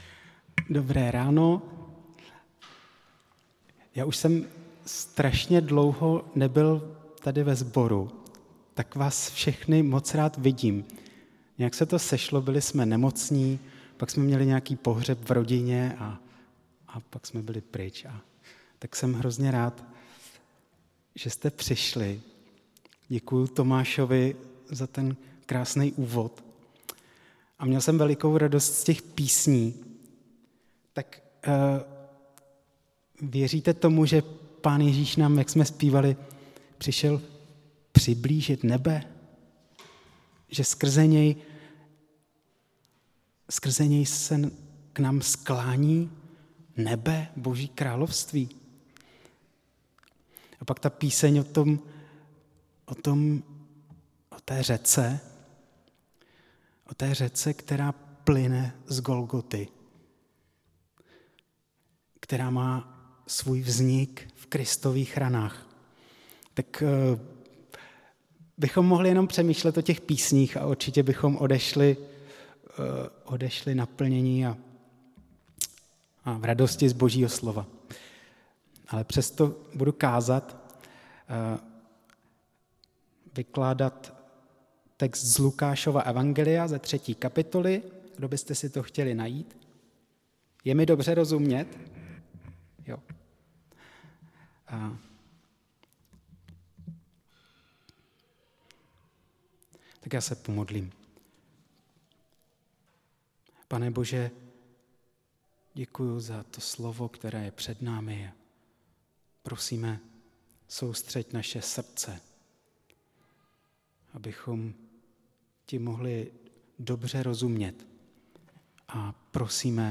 Kázání
Místo: Římská 43, Praha 2